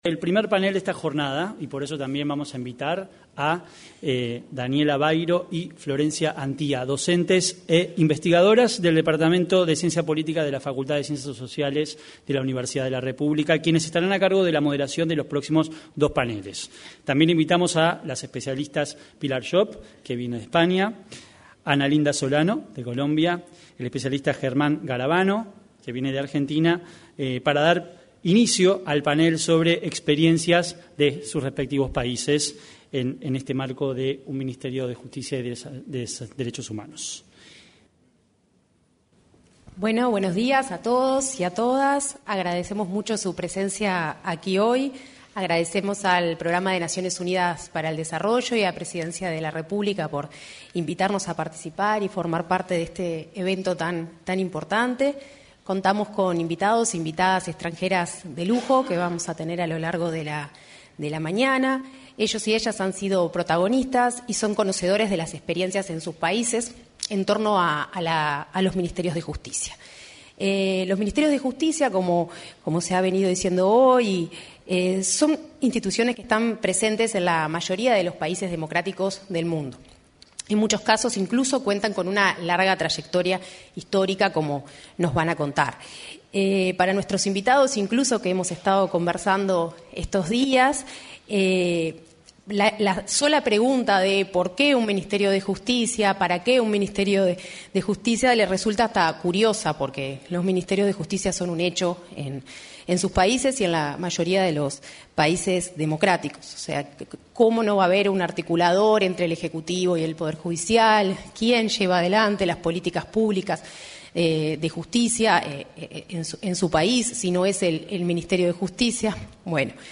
Seminario internacional Hacia un Ministerio de Justicia y Derechos Humanos en Uruguay